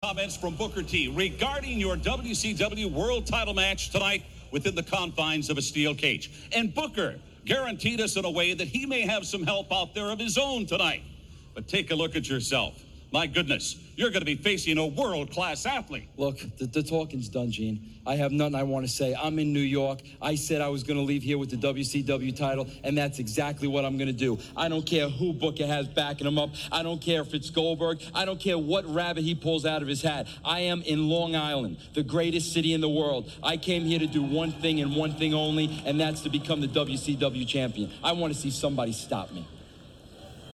Right before the main event, we get another Vinny Ru interview, this time with “Mean” Gene Okerlund.